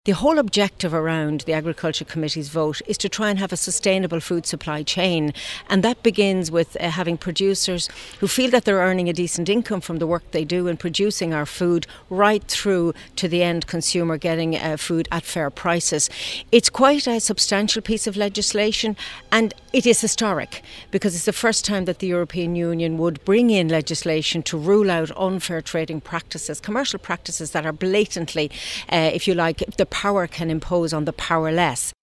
Parliament Vice President Mairead McGuinness, a member of the Committee, says the new law will allows farmers to have their concerns addressed by an authority which must act and investigate: